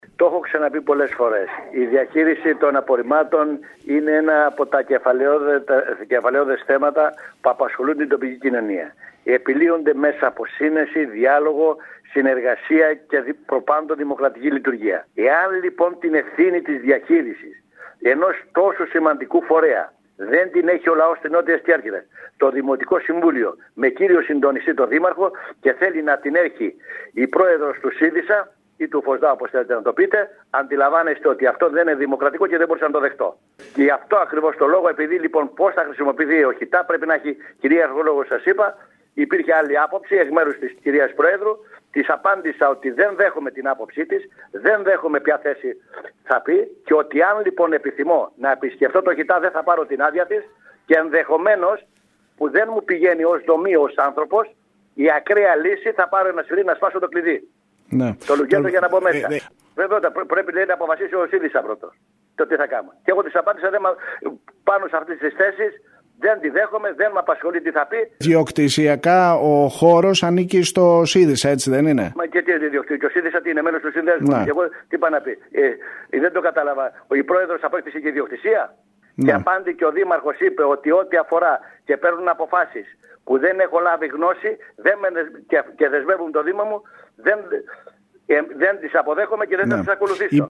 Ο κ. Λέσσης μιλώντας στο σταθμό μας ανέφερε ότι ο ίδιος, ως εκπρόσωπος του Δημοτικού Συμβουλίου Νότιας Κέρκυρας έχει το δικαίωμα ελεύθερης πρόσβασης στο ΧΥΤΑ Νότου ενώ τόνισε ότι θα διεκδικήσει την πρόσβαση αυτή με κάθε μέσο.